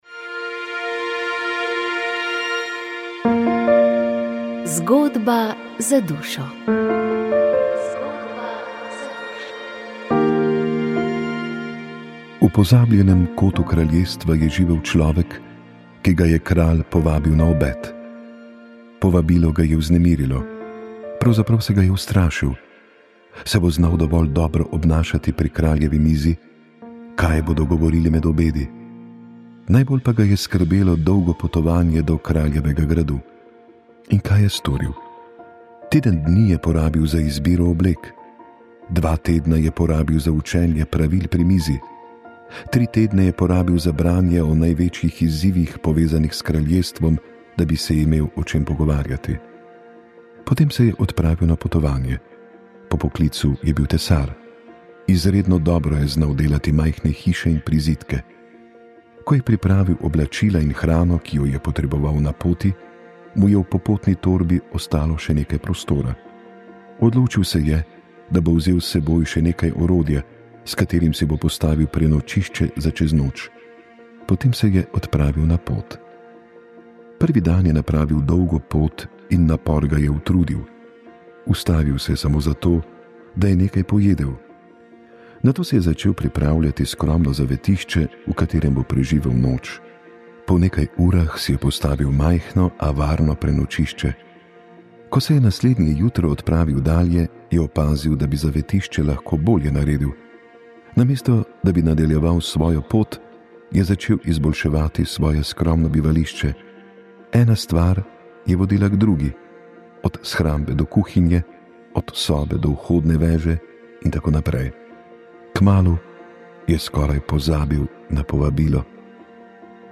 domovina komentar žiga turk peterson žižek